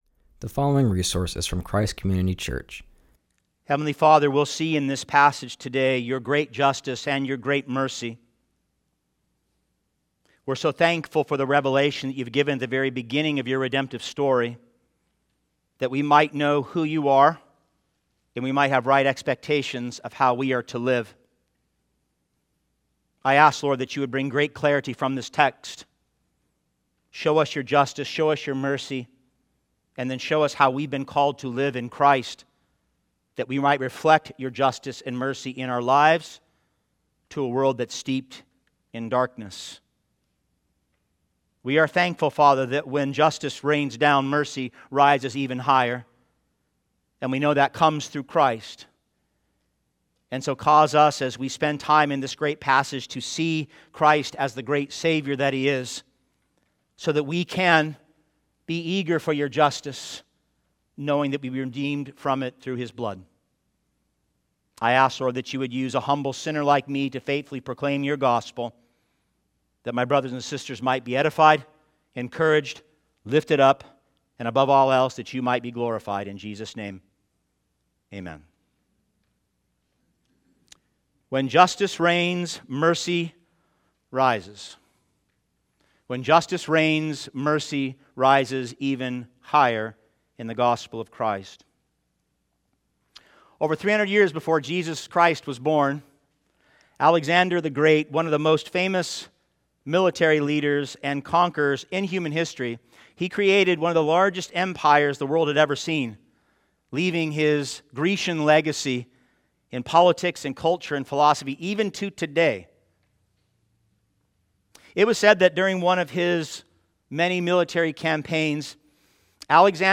continues our series and preaches from Genesis 6:9-22.